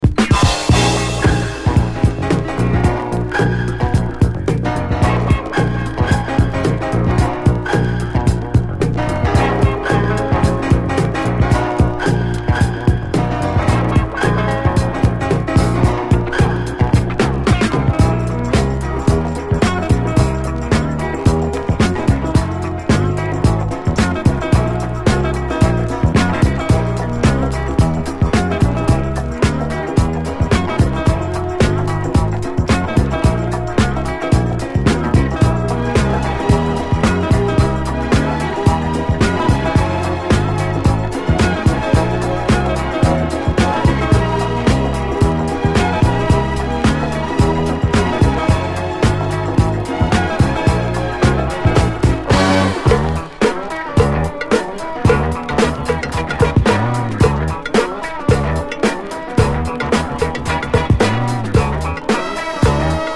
SOUL, 70's～ SOUL, 7INCH